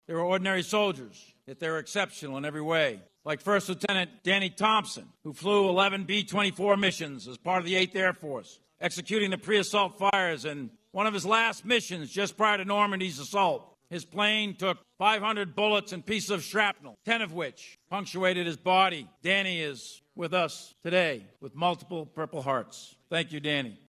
The senior Senator from Kansas joined General Mark Milley, Chairman of the Joint Chiefs of Staff and others who spoke about the sacrifice so many gave on D-Day.